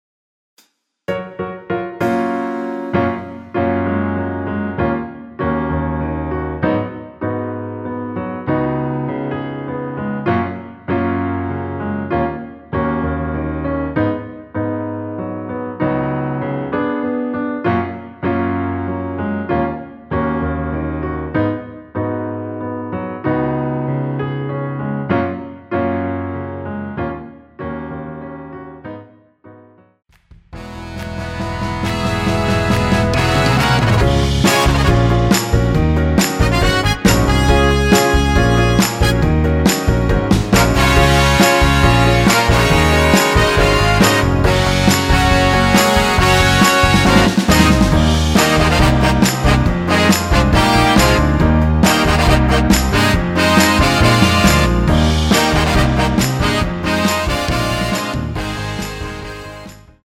전주 없이 시작 하는 곡이라 인트로 만들어 놓았습니다.
원키에서(-1)내린 MR입니다.
F#
앞부분30초, 뒷부분30초씩 편집해서 올려 드리고 있습니다.
중간에 음이 끈어지고 다시 나오는 이유는